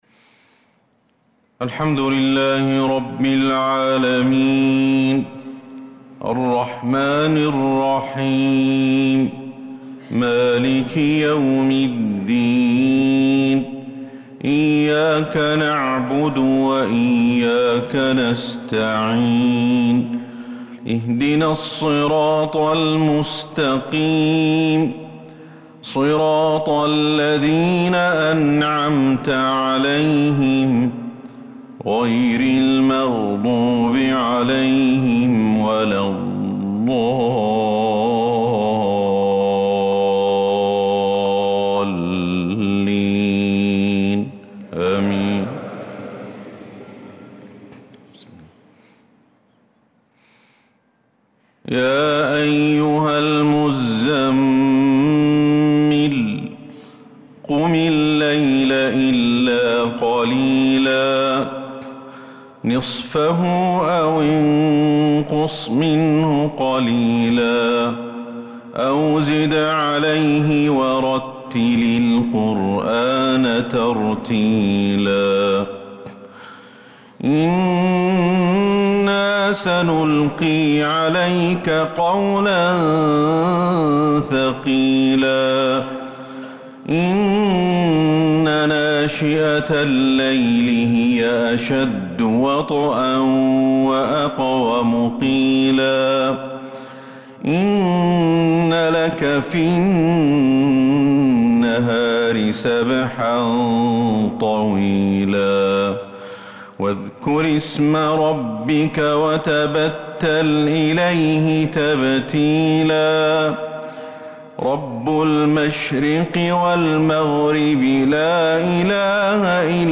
صلاة الفجر للقارئ أحمد الحذيفي 24 ذو القعدة 1445 هـ
تِلَاوَات الْحَرَمَيْن .